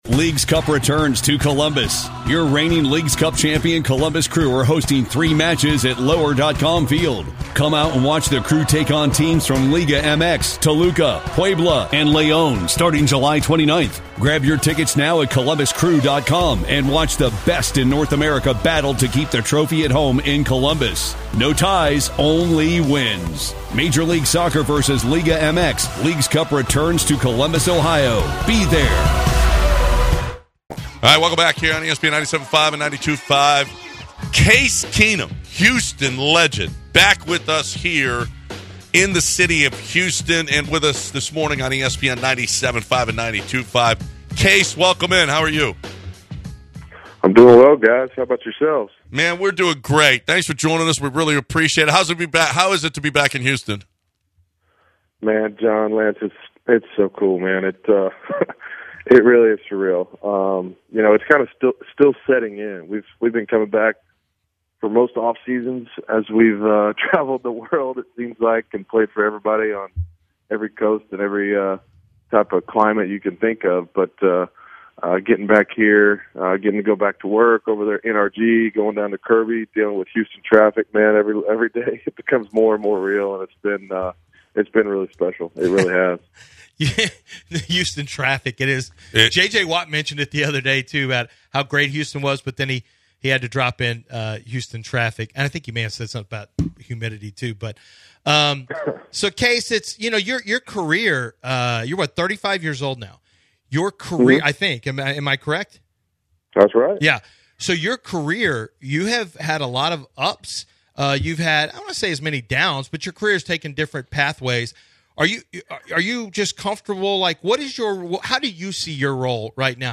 Houston Texans Quarterback Case Keenum joined The Bench